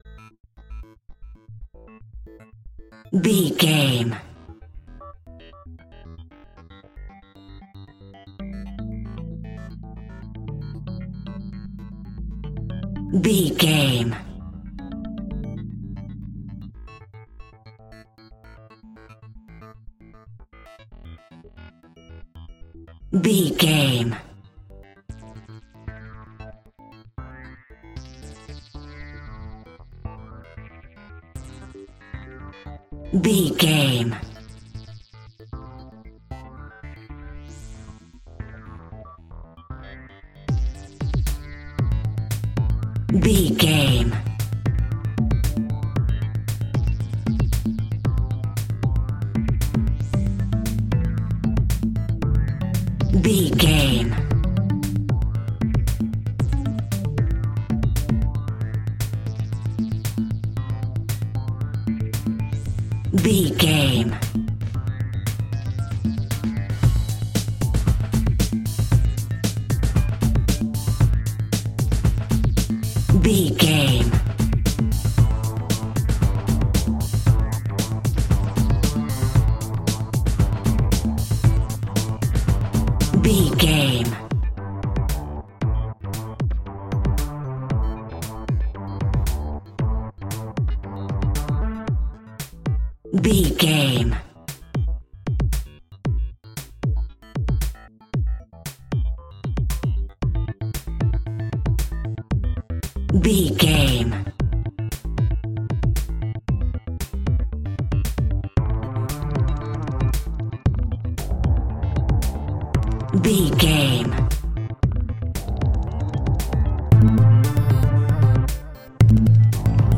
Aeolian/Minor
Slow
futuristic
hypnotic
dreamy
contemplative
synthesiser
electric guitar
drum machine
electronic
glitch
synth lead
synth bass